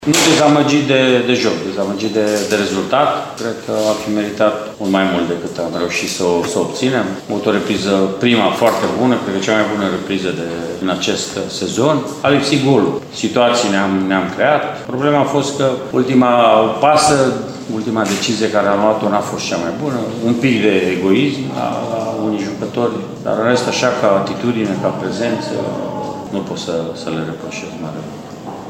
Antrenorul arădenilor, Mircea Rednic, spune că a fost nemulțumit doar de rezultat, nu și de joc: